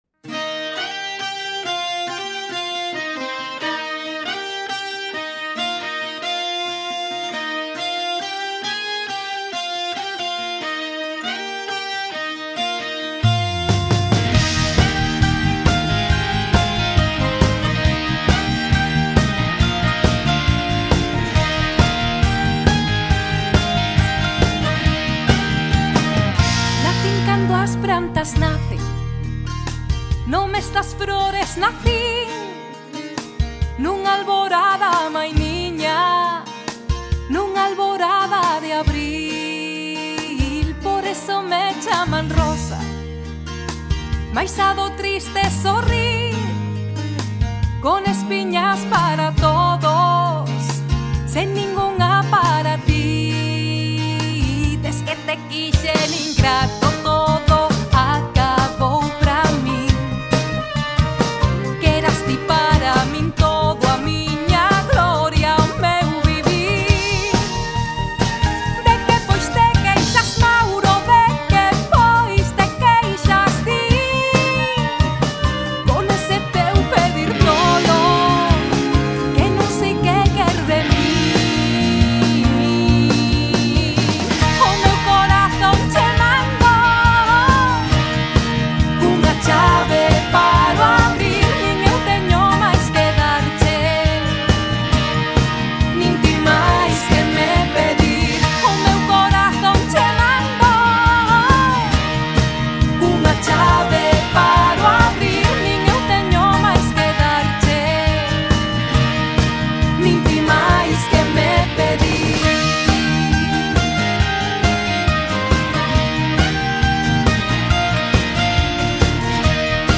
folk-pop